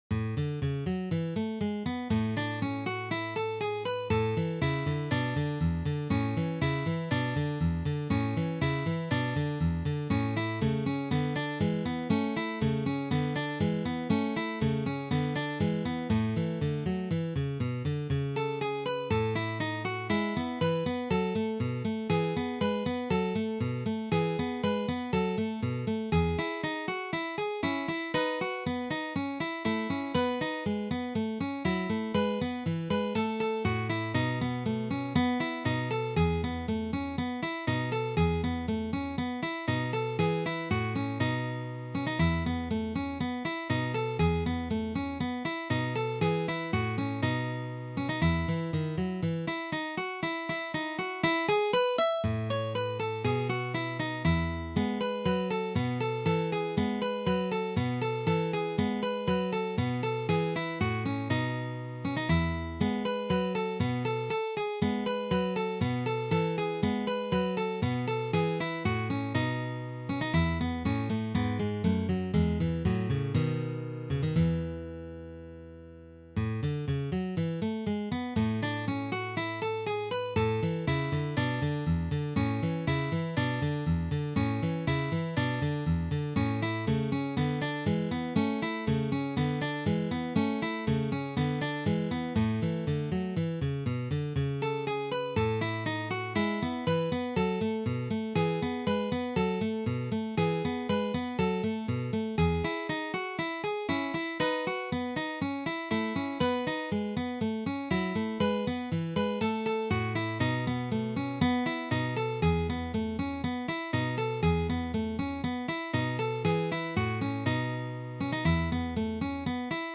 Sonata L. 395 by Domenico Scarlatti is an Advanced Level duet for two guitars. The highest pitch is high G, first string, fifteenth fret. The rhythms are mostly intermediate level except for the dotted quarter-two sixteenth rhythms.